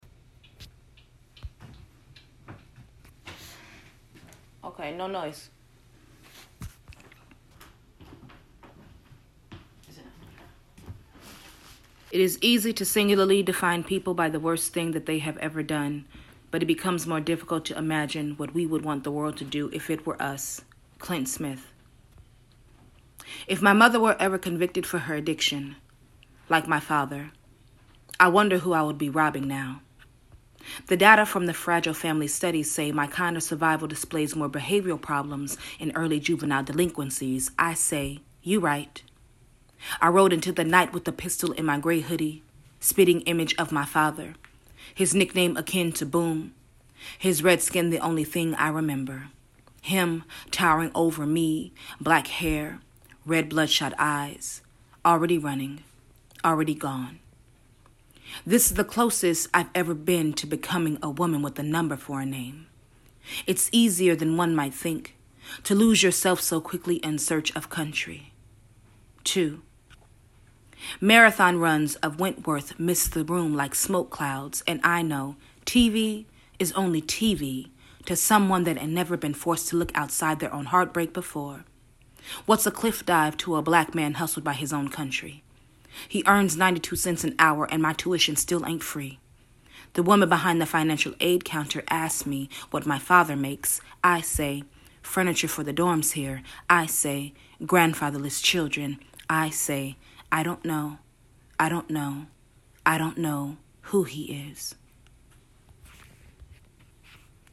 reads an excerpt from